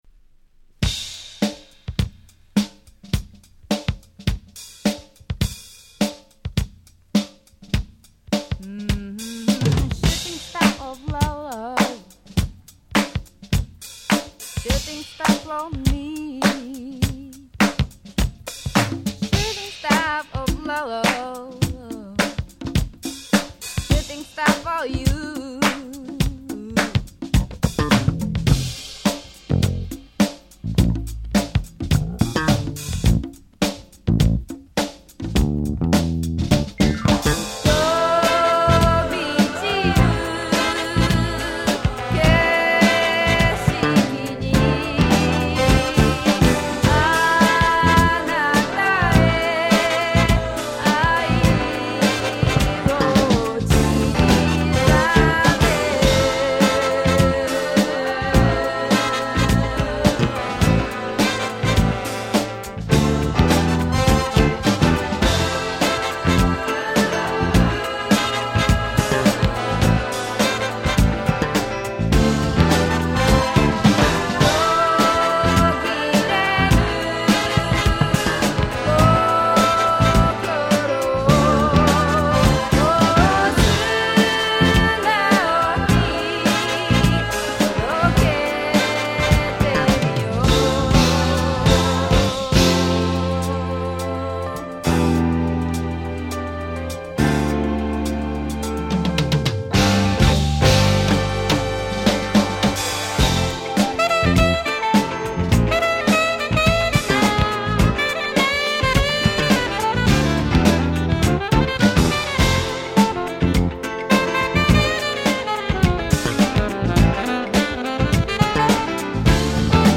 試聴ファイルは別の盤から録音してあります。